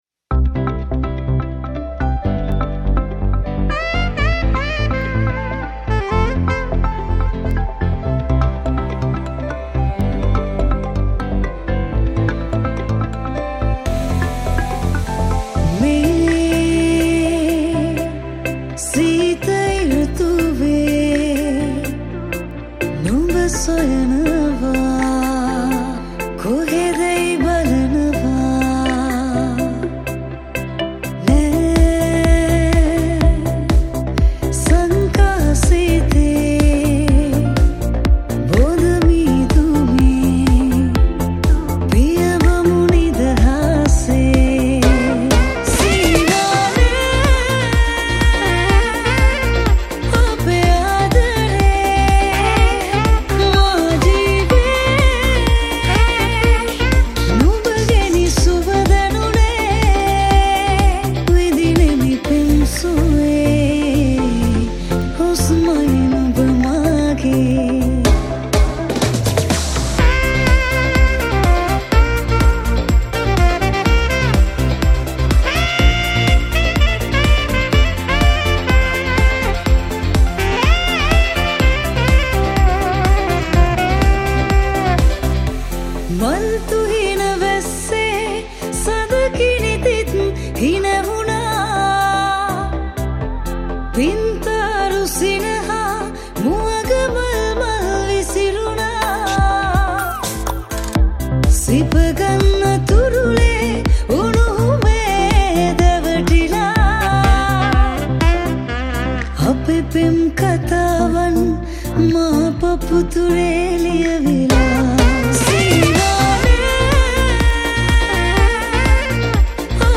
Saxophone